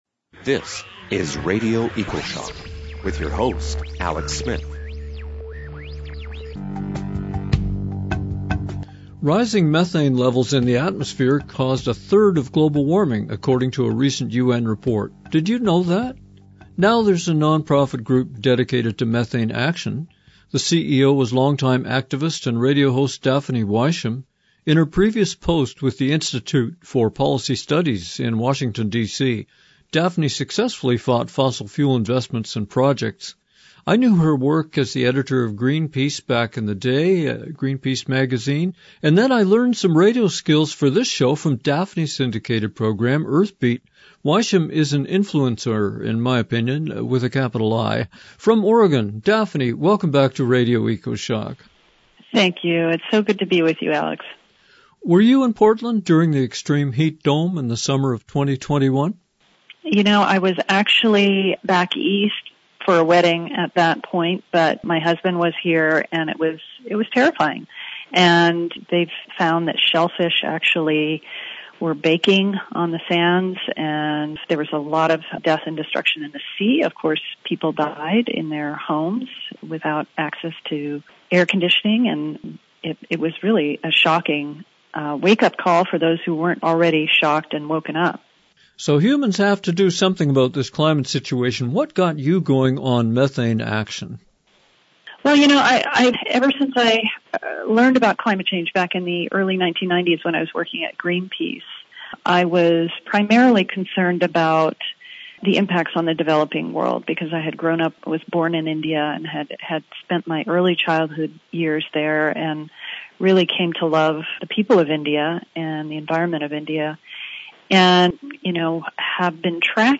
Listen to/download this Radio Ecoshock show in CD Quality (57 MB) or Lo-Fi (16 MB)